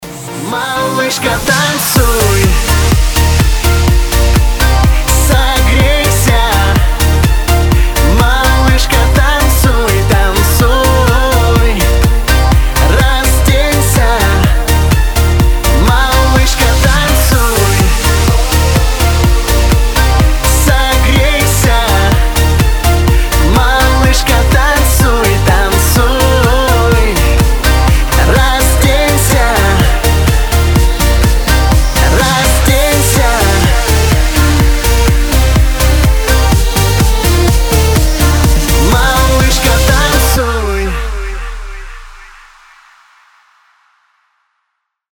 заводные